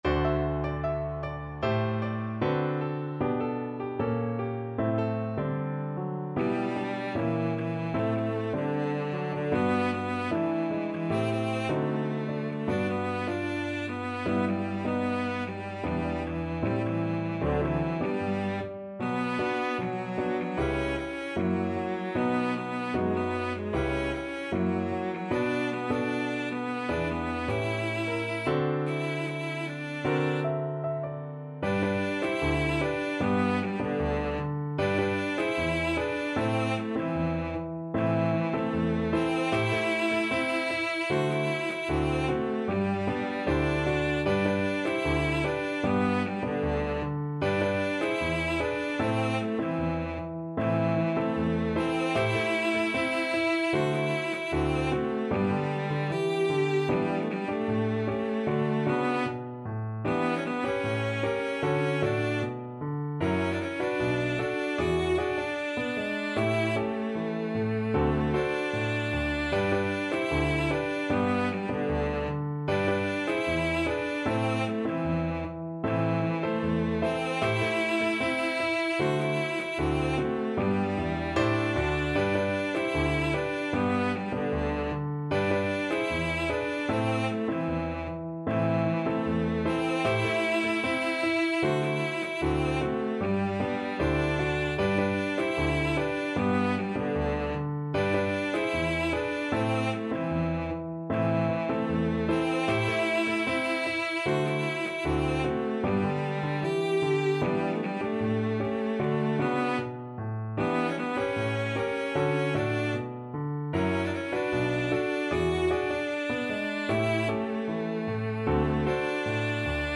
2/2 (View more 2/2 Music)
D4-G5
Pop (View more Pop Cello Music)